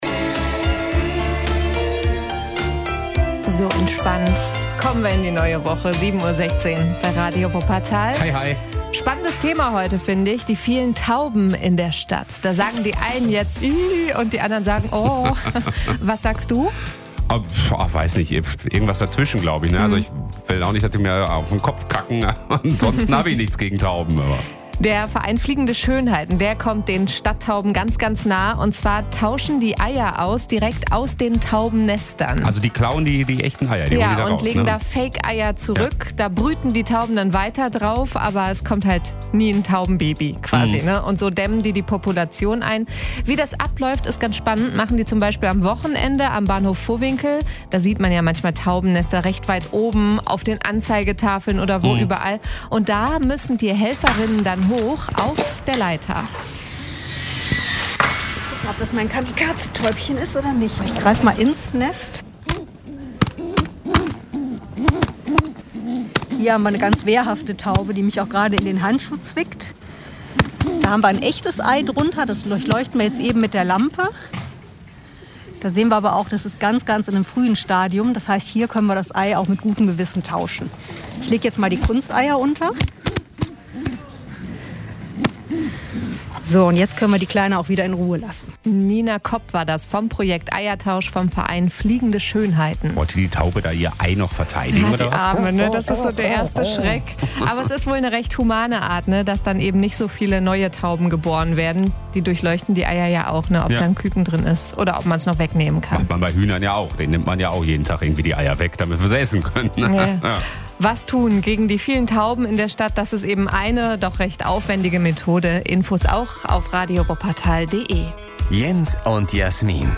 Reportage: Eiertausch